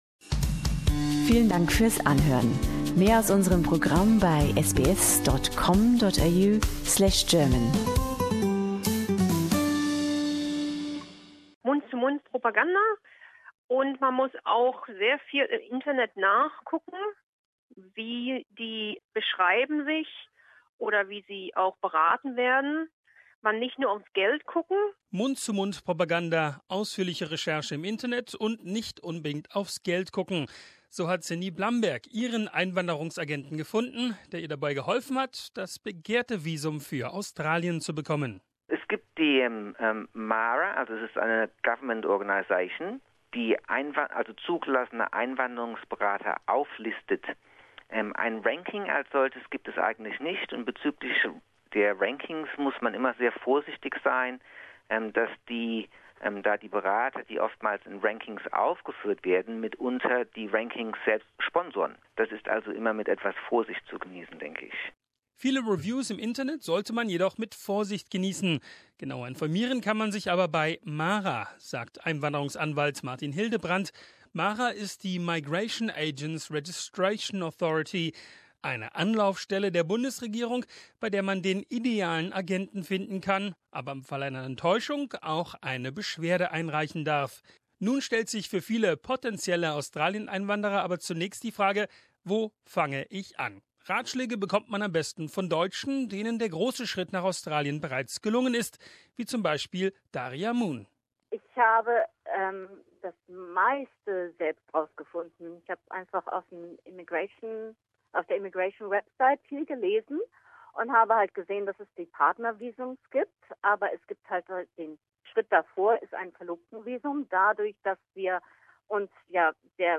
spricht mit erfolgreichen Antragstellern und einem Experten